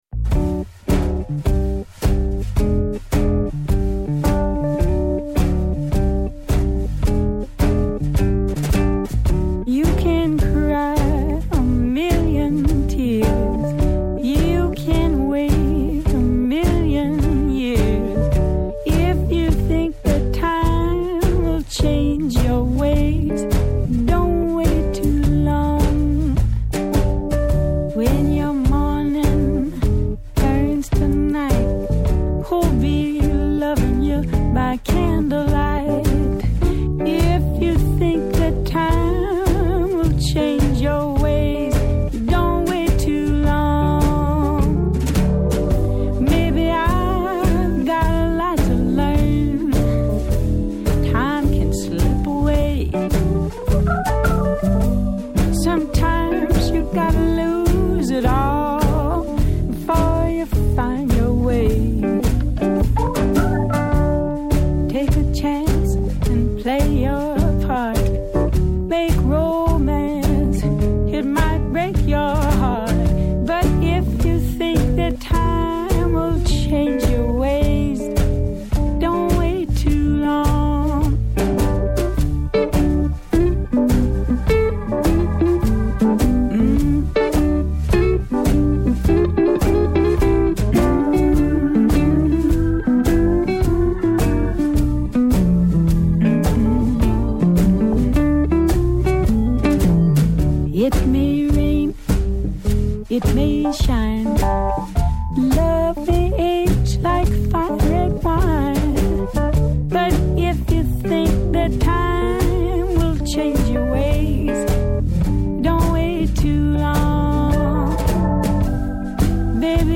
Καλεσμένος σήμερα στο studio